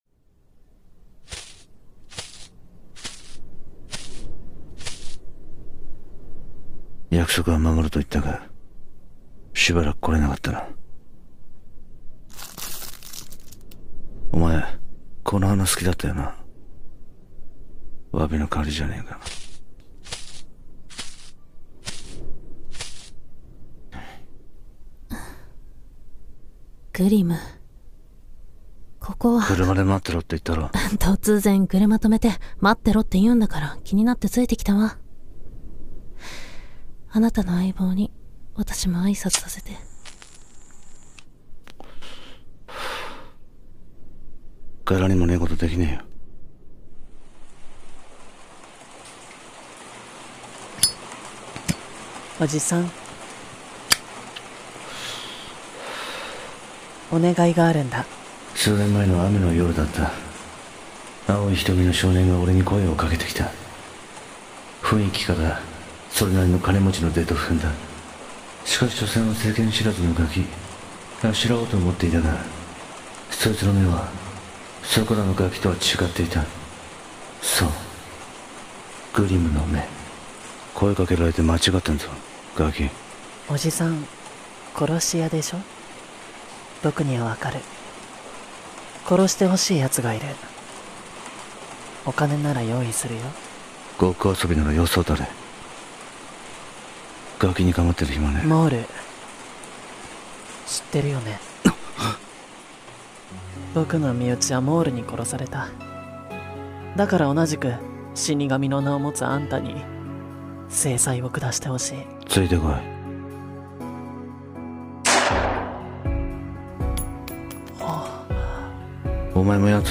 【三人声劇】Jasper-Episode of GLIM CHILD-